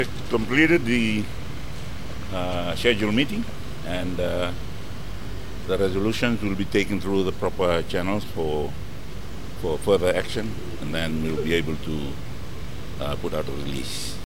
Prime Minister Sitiveni Rabuka after the COC meeting.
Prime Minister Sitiveni Rabuka talking to the media.